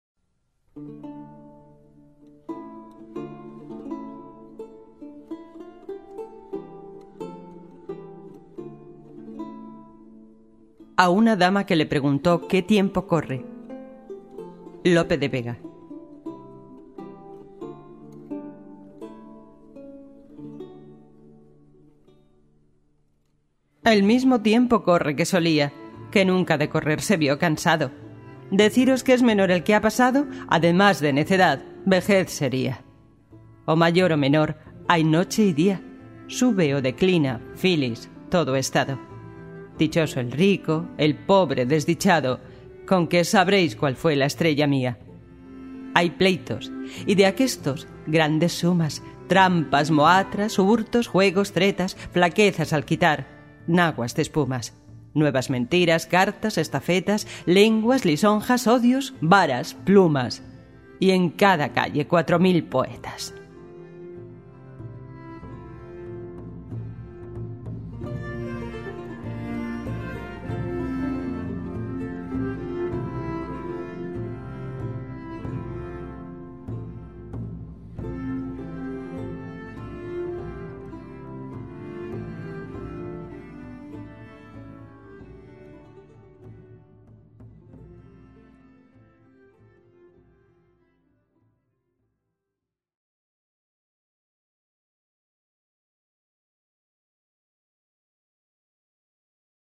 Poema